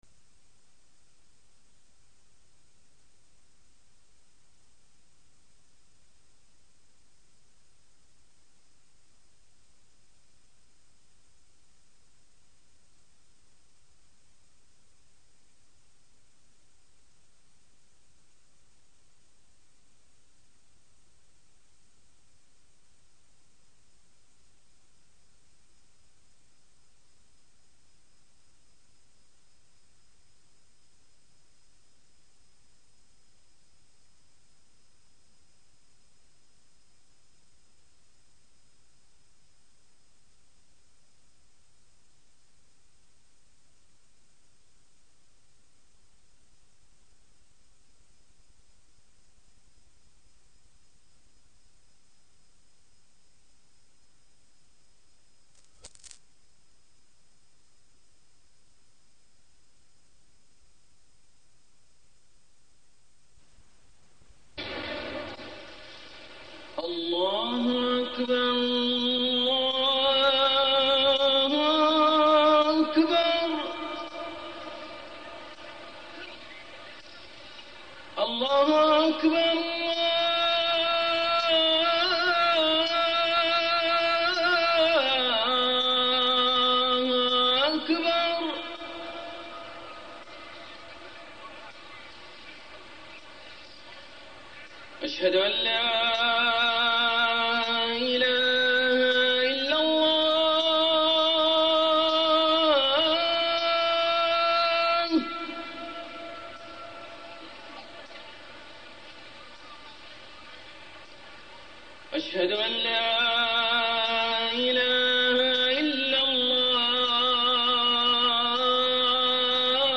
تاريخ النشر ٢٥ شوال ١٤٢٤ هـ المكان: المسجد الحرام الشيخ: معالي الشيخ أ.د. صالح بن عبدالله بن حميد معالي الشيخ أ.د. صالح بن عبدالله بن حميد الإيمان